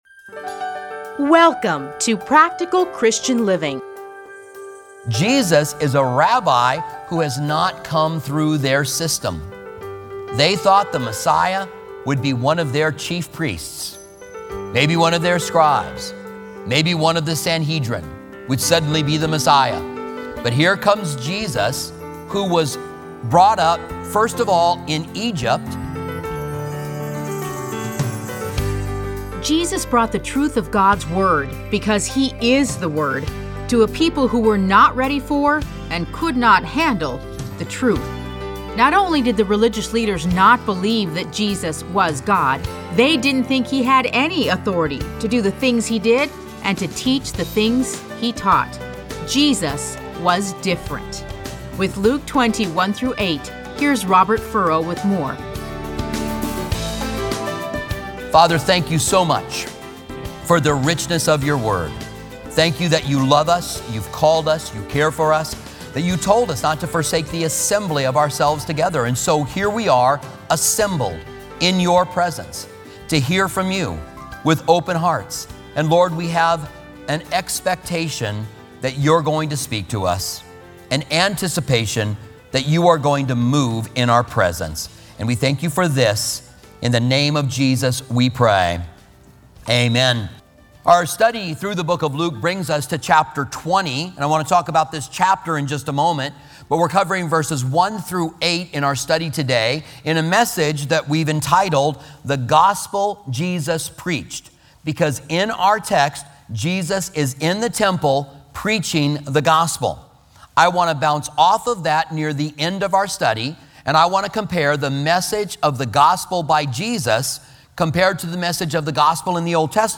Listen to a teaching from Luke 20:1-8.